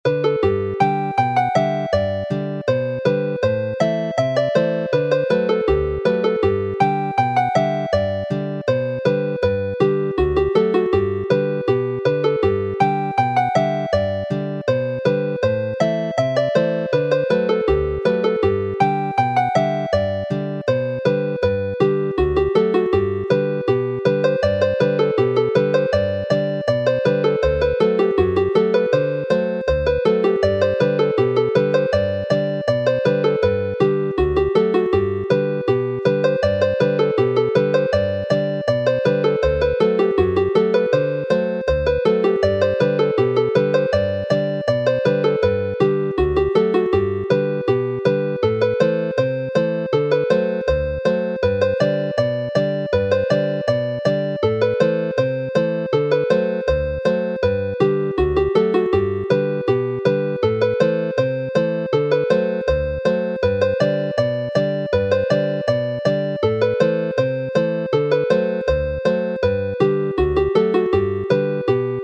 Chwarae'r alaw